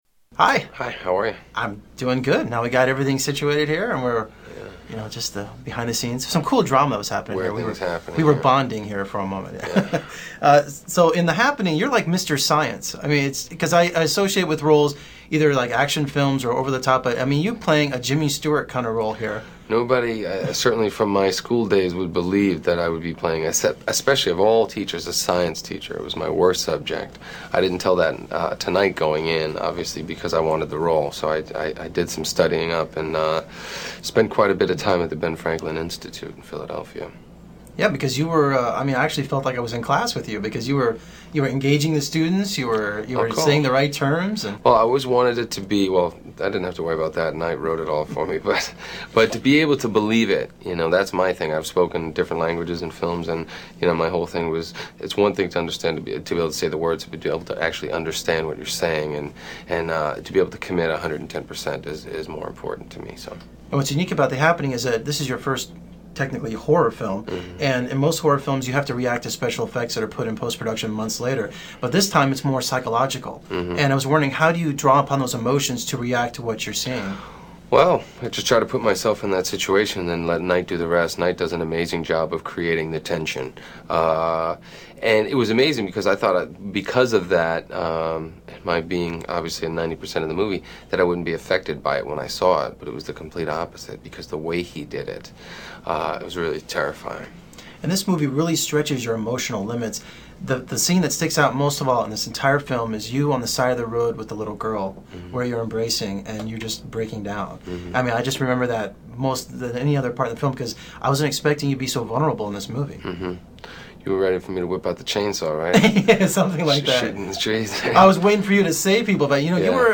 Mark Wahlberg Interview